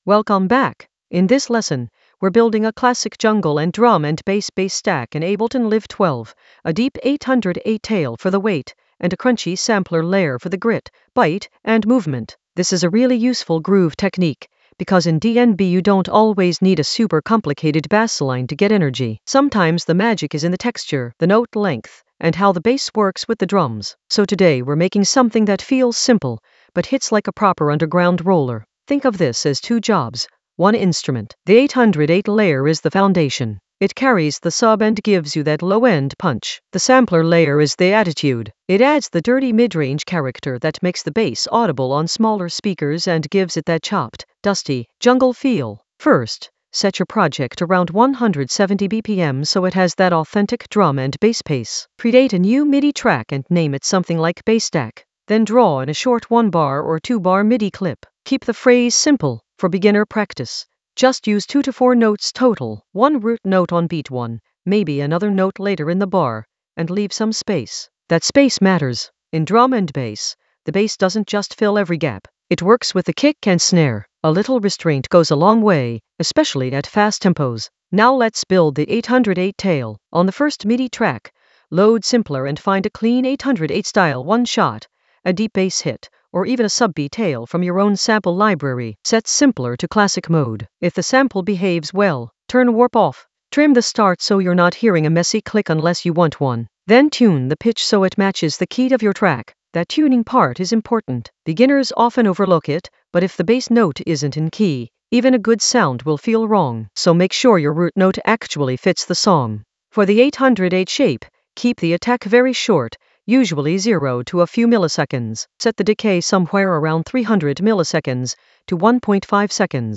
An AI-generated beginner Ableton lesson focused on Stack jungle 808 tail with crunchy sampler texture in Ableton Live 12 in the Groove area of drum and bass production.
Narrated lesson audio
The voice track includes the tutorial plus extra teacher commentary.